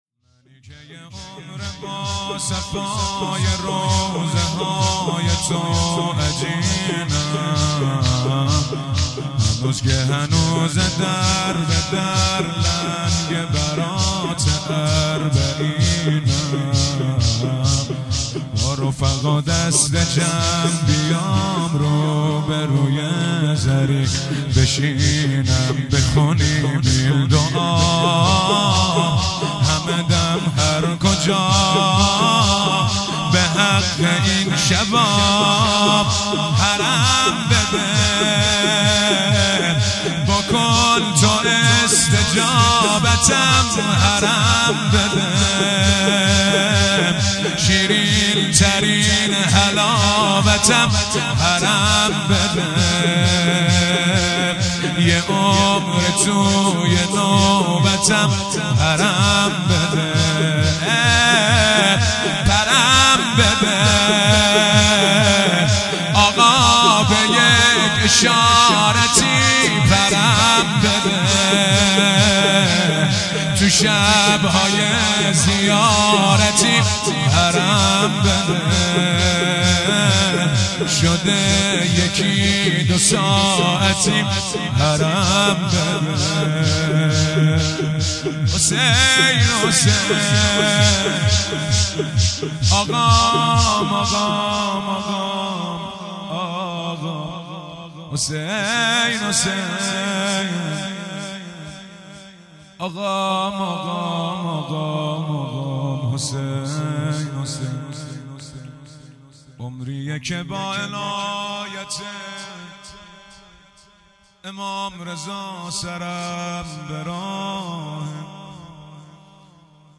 جلسه هفتگي،هيئت محبان العباس،شورپایانی جدید /من که یه عمر